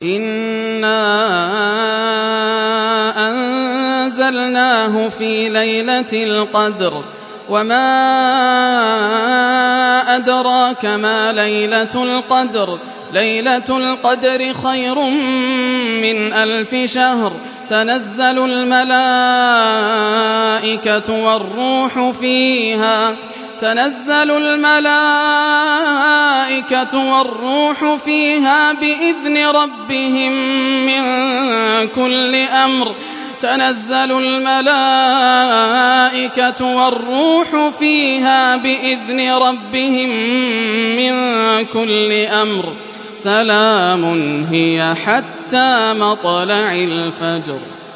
Kadir Suresi İndir mp3 Yasser Al Dosari Riwayat Hafs an Asim, Kurani indirin ve mp3 tam doğrudan bağlantılar dinle